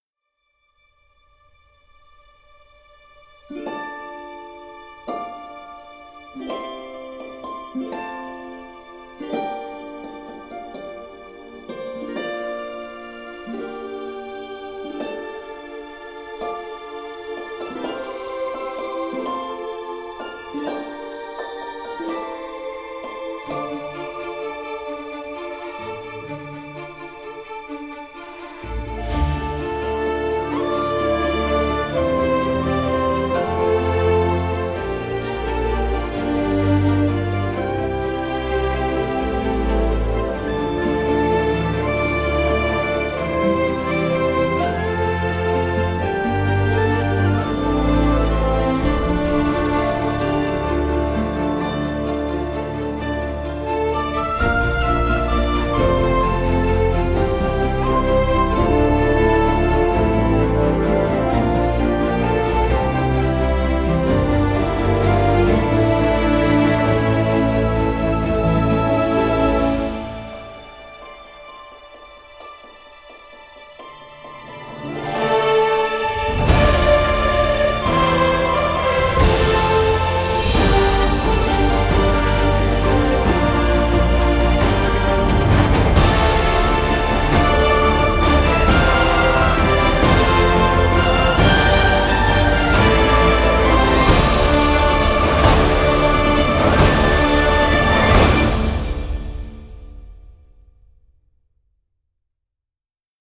这种混合乐器产生了类似于扬琴或钢片琴的音色，但是钢琴的长弦和大音板使得乐器具有更丰富的音色和更长的延音。
该音源使用了14种顶级麦克风（来自DPA，Coles，Neumann）在钢琴周围和房间后方进行录制。
最高力度触发短暂的一击滚动 - 这是扬琴音乐中常用的演奏技巧。